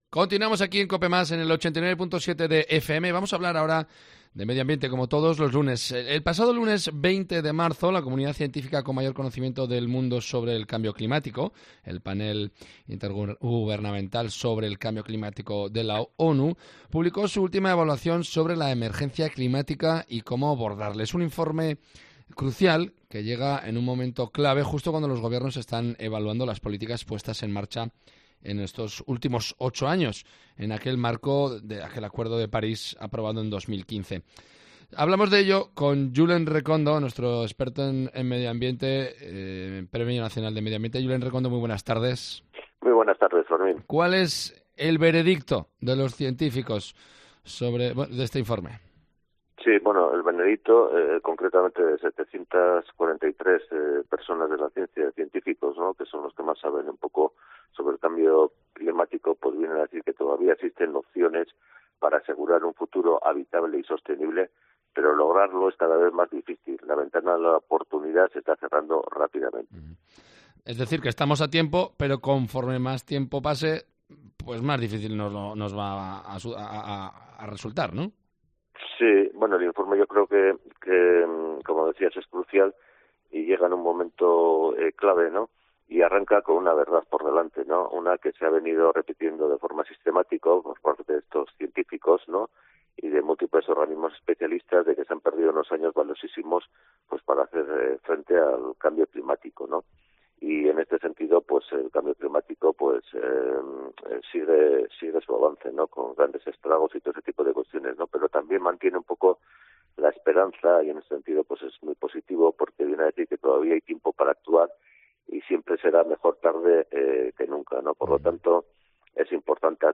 en Cope Navarra.